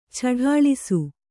♪ chaḍāḷisu